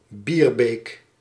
Bierbeek (Dutch pronunciation: [ˈbiːrbeːk]